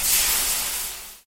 fizz.mp3